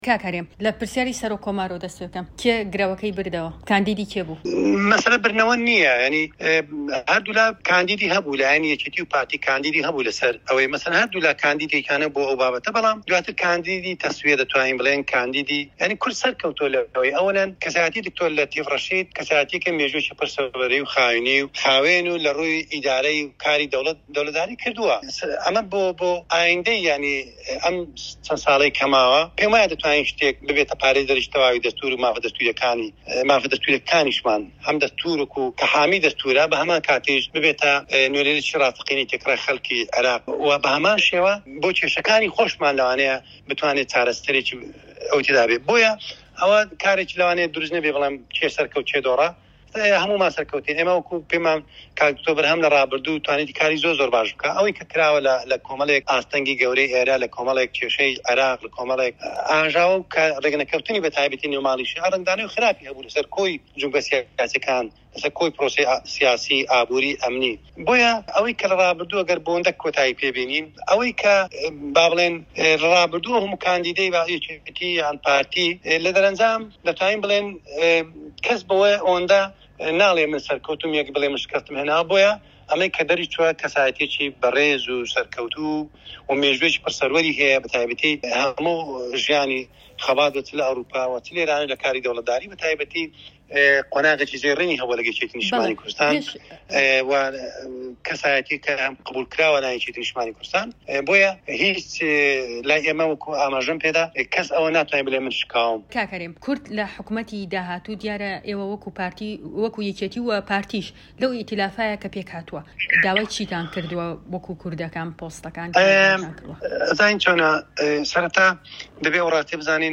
دەقی وتووێژەکەی هەرێم کەمال ئاغا